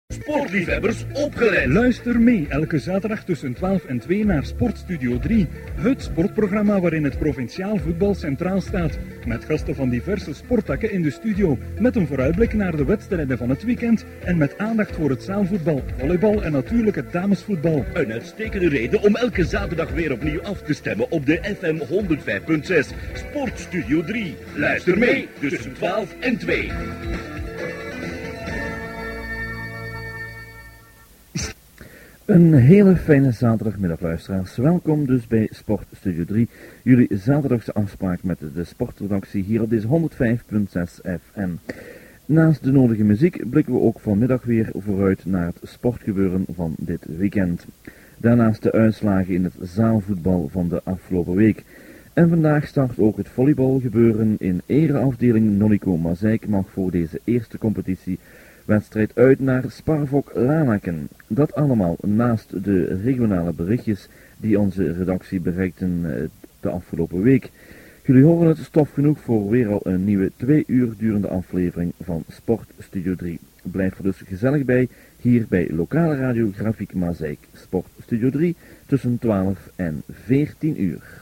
Hier een fragment uit een uitzending.